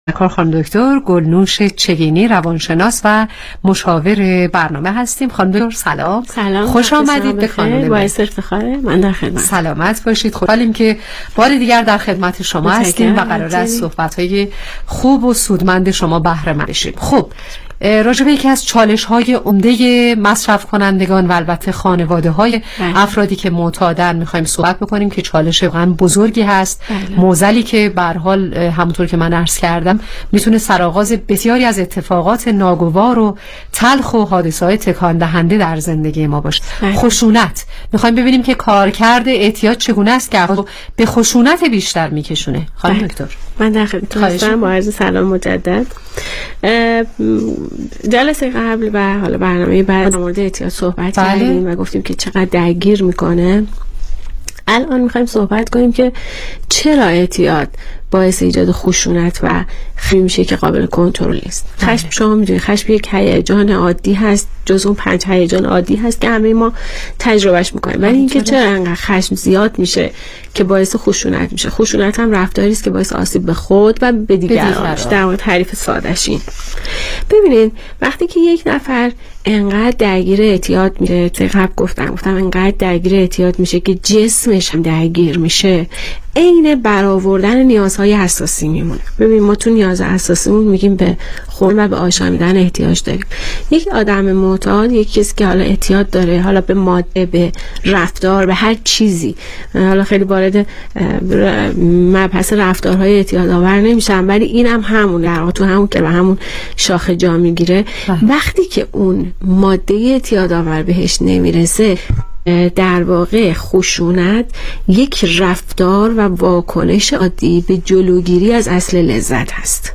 برنامه رادیویی کانون مهر